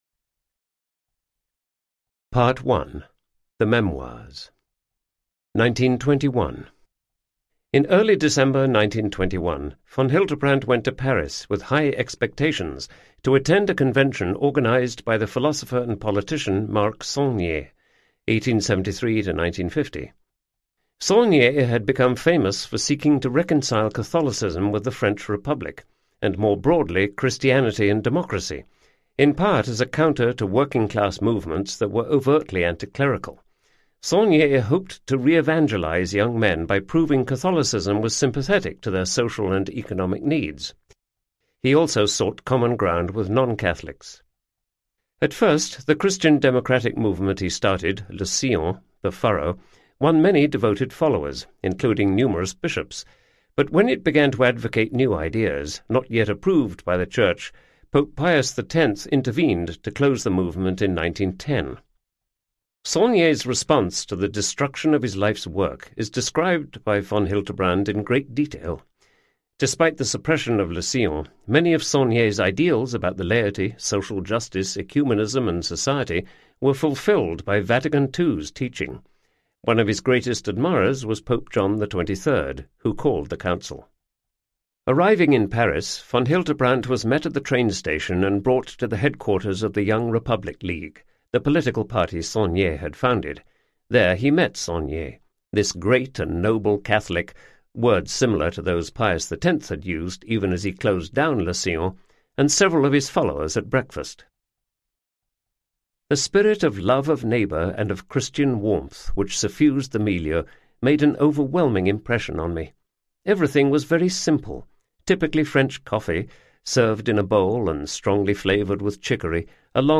My Battle Against Hitler Audiobook
Narrator
11.1 Hrs. – Unabridged